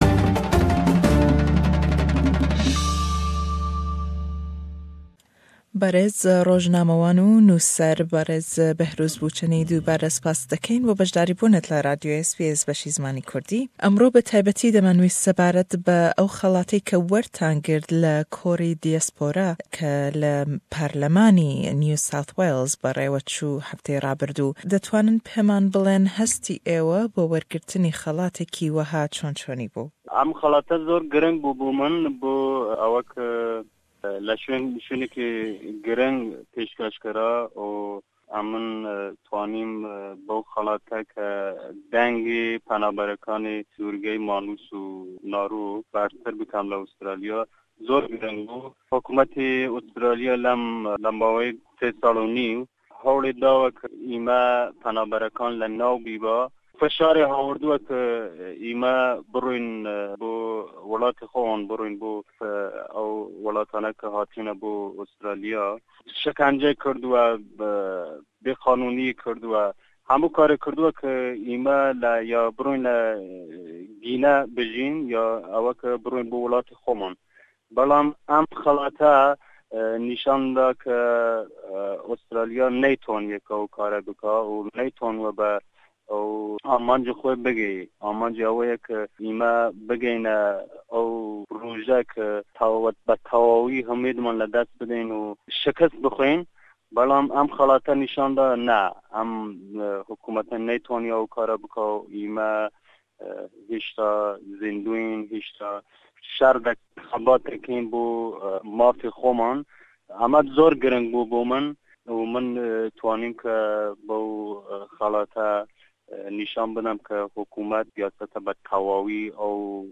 Kurdish journalist and human rights, who has been held on Manus Island by the Australian government, was awarded by Diaspora Symposium in NSW Parliament House in Sydney, for Social Justice. In this interview we ask him about the award and the latest about the situation of off shore asylum-seekers.